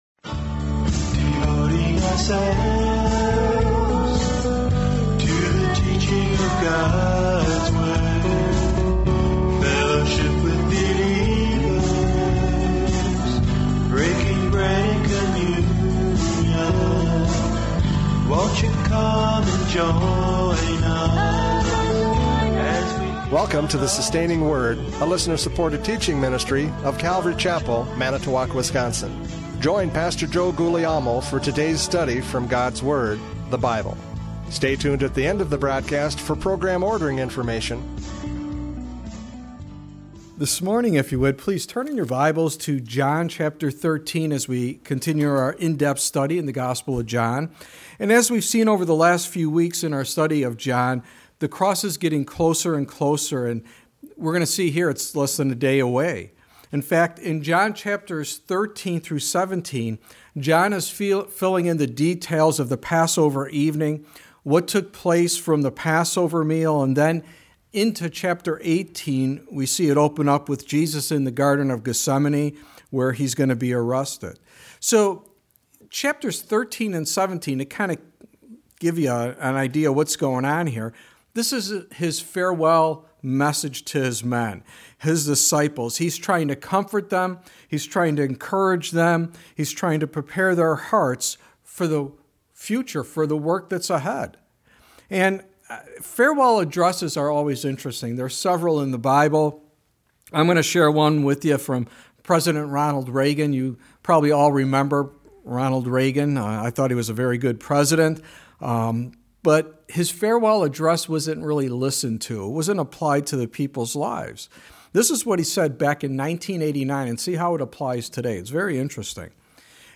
John 13:1-20 Service Type: Radio Programs « John 12:44-50 The Last Call!